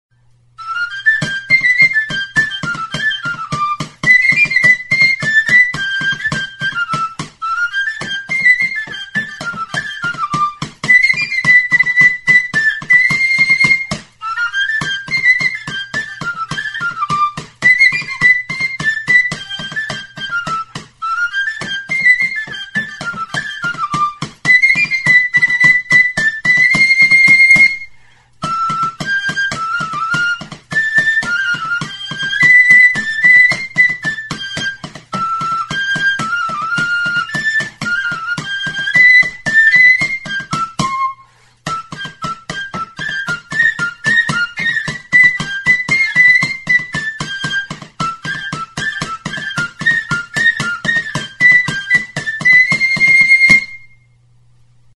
Music instrumentsTXISTUA
Aerophones -> Flutes -> Fipple flutes (one-handed)
Recorded with this music instrument.
3 zuloko flauta zuzena da, 3 zatian egina (Fa eta Fa#).